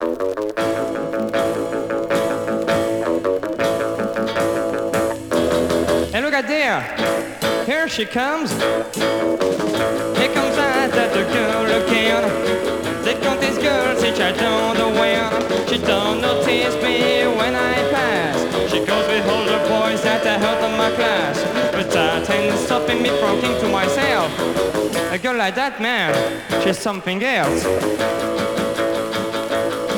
R'n'r